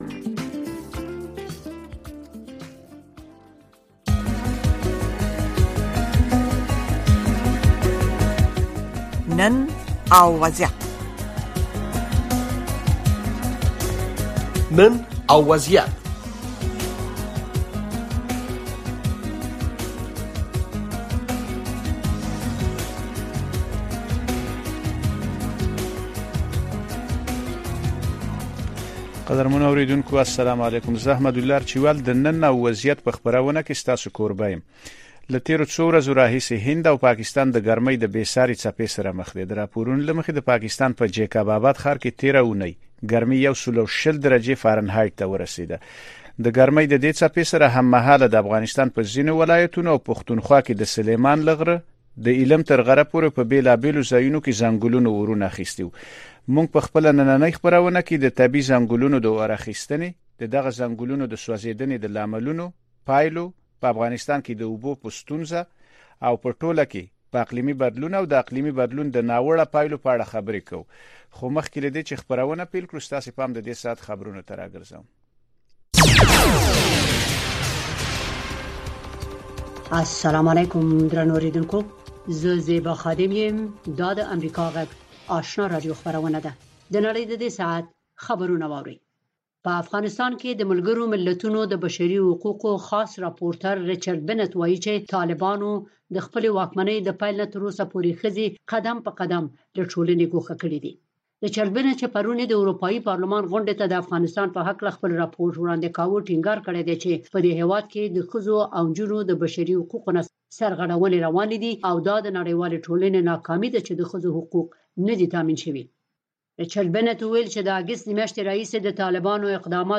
د نړۍ سیمې او افغانستان په روانو چارو او د نن په وضعیت خبرونه، راپورونه، مرکې او تحلیلونه